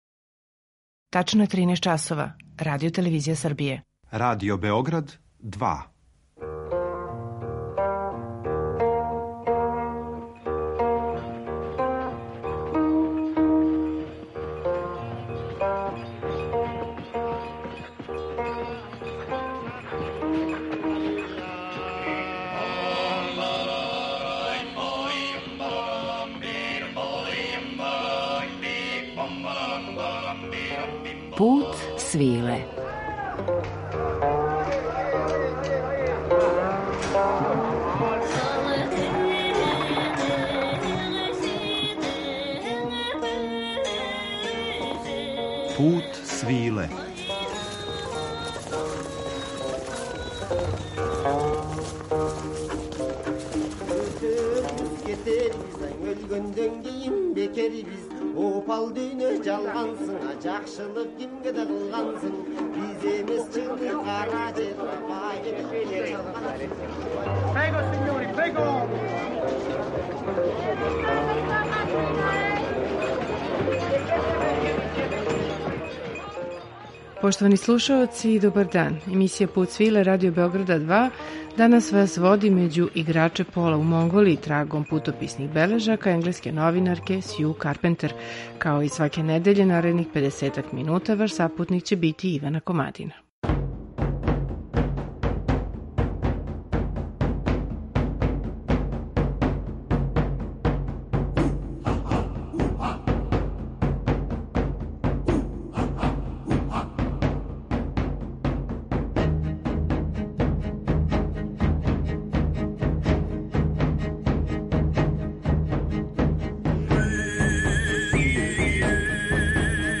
Монголија и поло уз монголску групу Егшиглен.